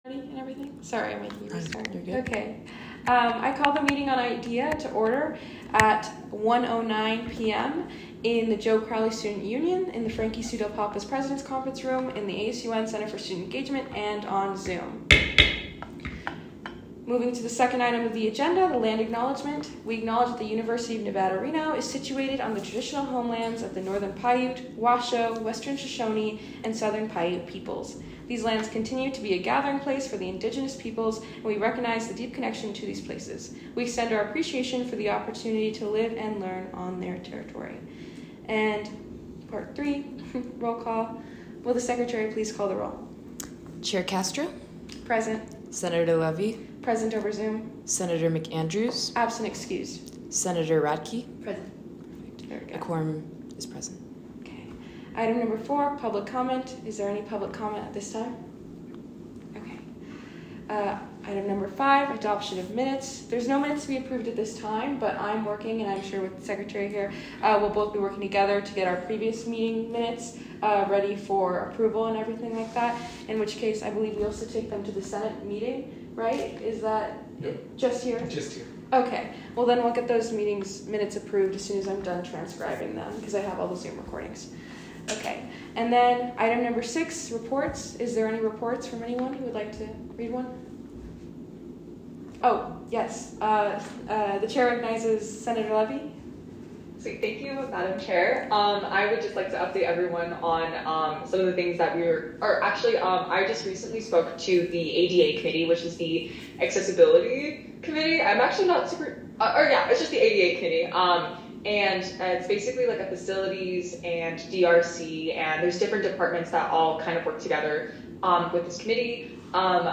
Location : Frankie Sue Del Papa Conference Room
Audio Minutes